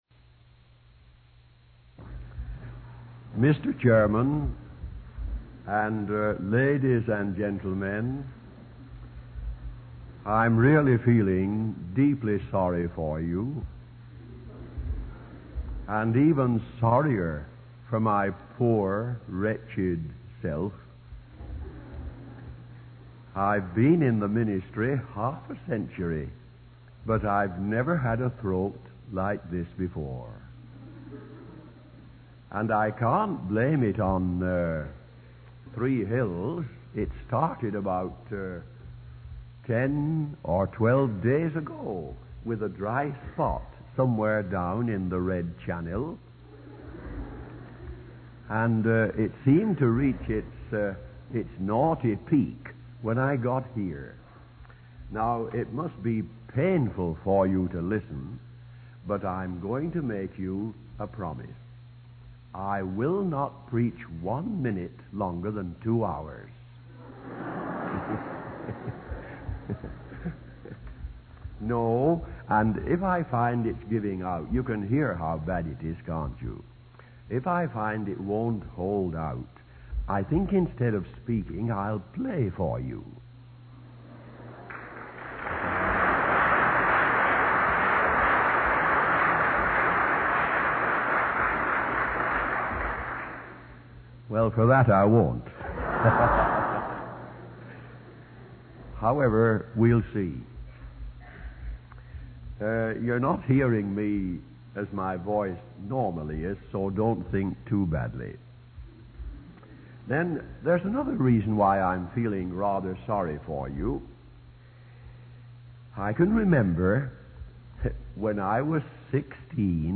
In this sermon, the speaker shares personal anecdotes and reflections on the impact of Jesus in his life. He recalls his mother's faith and the influence of evangelists in his town.